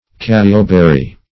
Search Result for " cassioberry" : The Collaborative International Dictionary of English v.0.48: Cassioberry \Cas"si*o*ber`ry\, n. [NL. cassine, from the language of the Florida Indians.]
cassioberry.mp3